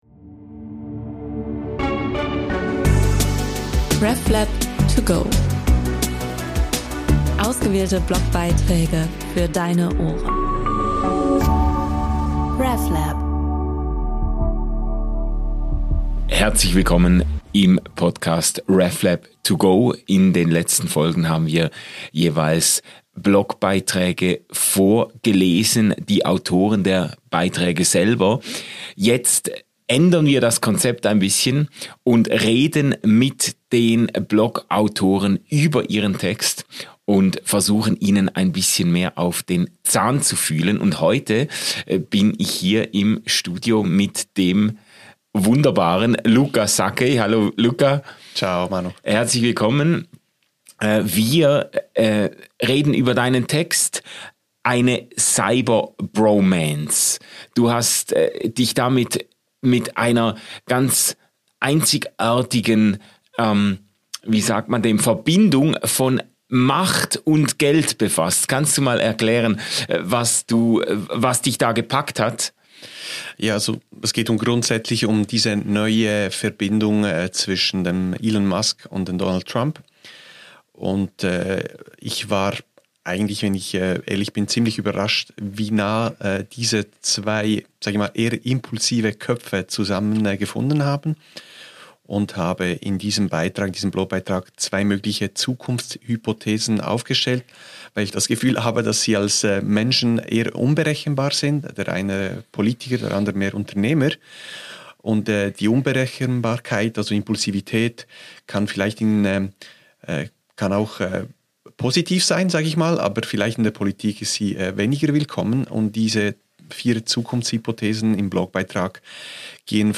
Wir hatten jeweils unsere Blogbeiträge vorgelesen, wir reden nun kurz darüber und geben euch Hintergrund-Informationen weiter.